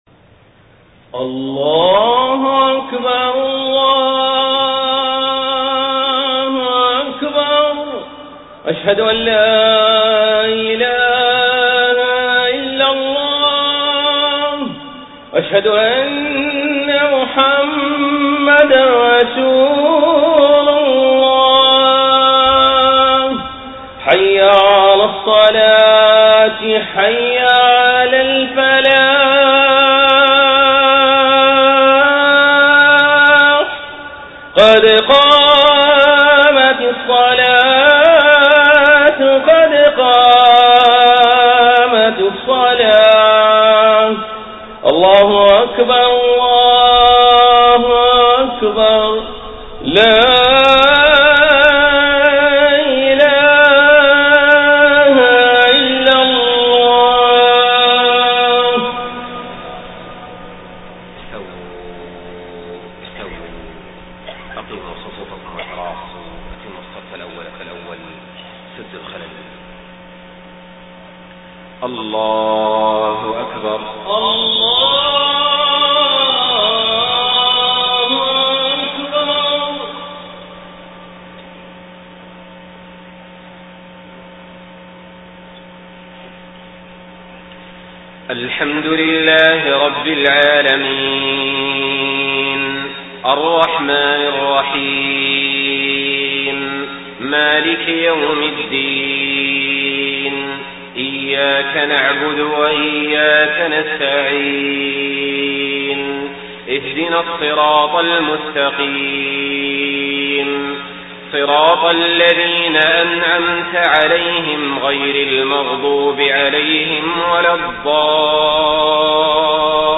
صلاة العشاء 6 ربيع الأول 1431هـ خواتيم سورة النبأ 17-40 > 1431 🕋 > الفروض - تلاوات الحرمين